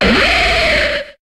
Cri d'Aéroptéryx dans Pokémon HOME.